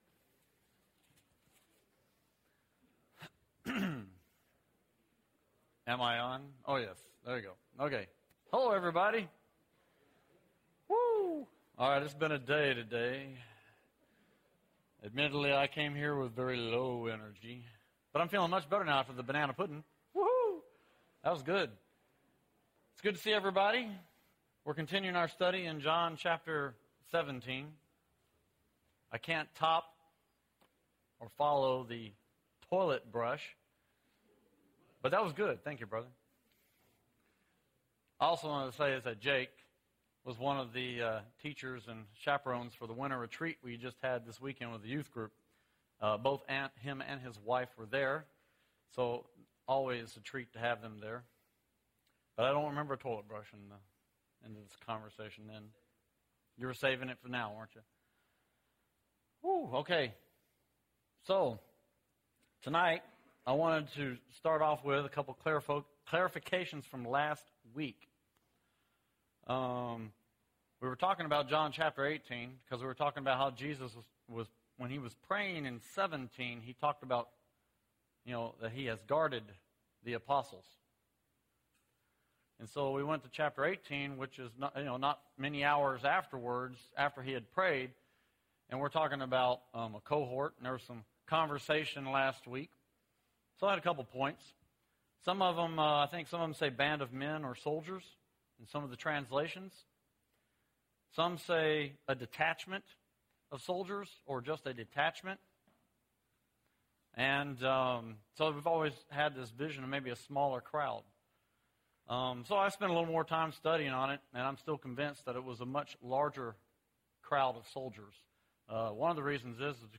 One Perfected in Unity (4 of 8) – Bible Lesson Recording